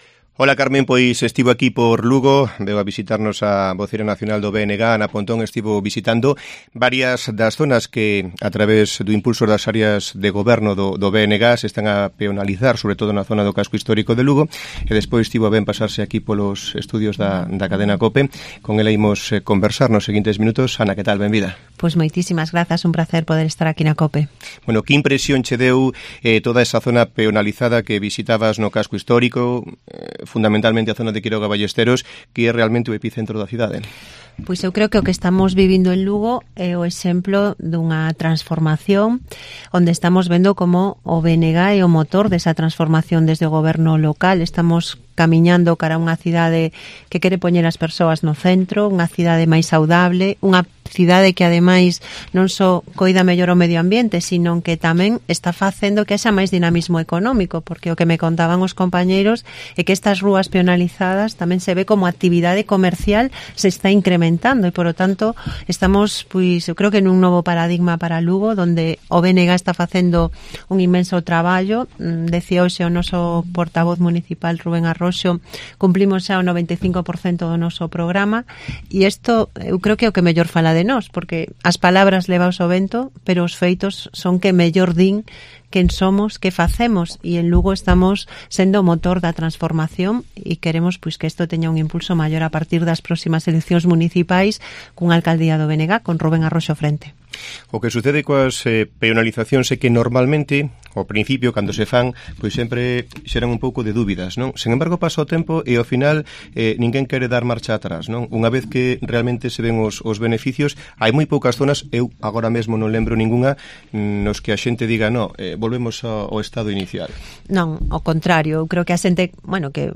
Pontón realizó esta mañana una visita a la zona de Quiroga Ballesteros, en el casco histórico de la ciudad de Lugo, peatonalizada durante este mandato por el área de gobierno del BNG en el Ayuntamiento de Lugo, antes de pasarse por los estudios de Cope Lugo para una entrevista.
Entrevista a Ana Pontón en Cope Lugo